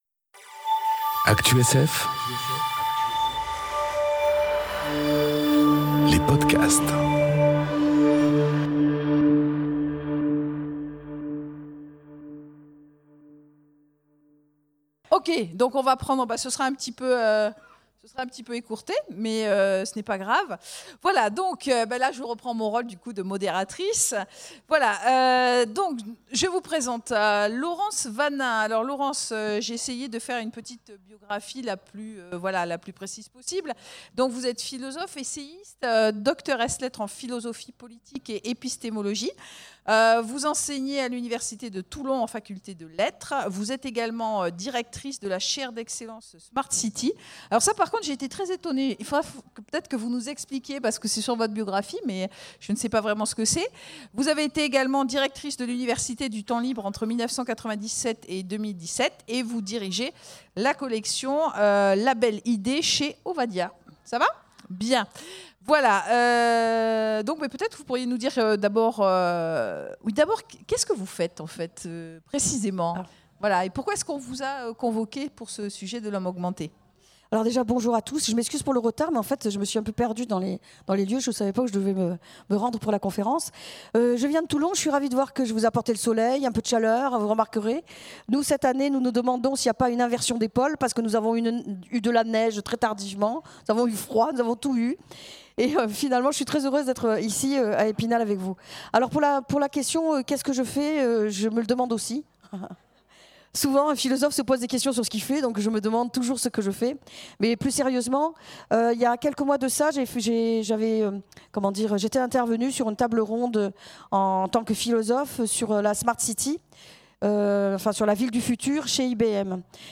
Conférence L'Homme augmenté : la créature du futur ? enregistrée aux Imaginales 2018